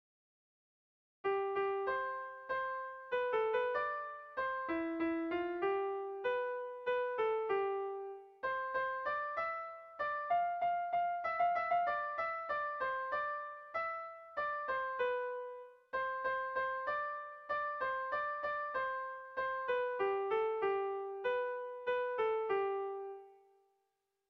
Bertso melodies - View details   To know more about this section
ABD